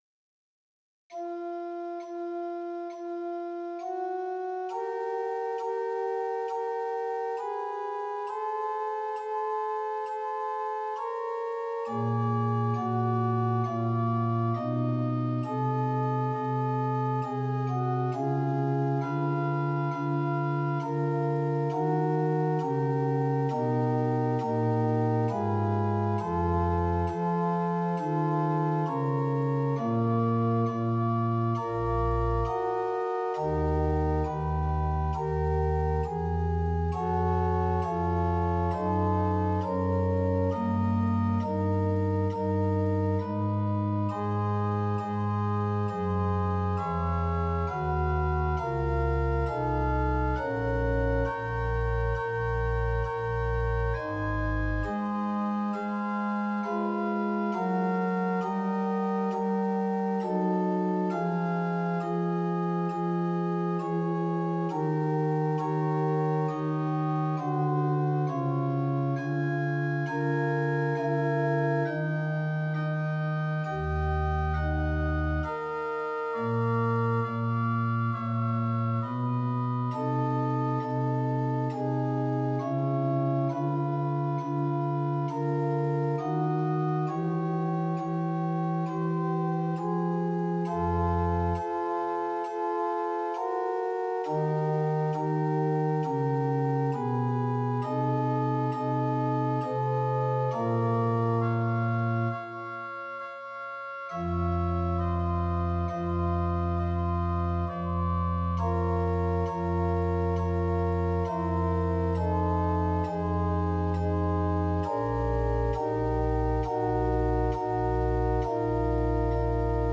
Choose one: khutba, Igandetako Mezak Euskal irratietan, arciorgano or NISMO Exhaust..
arciorgano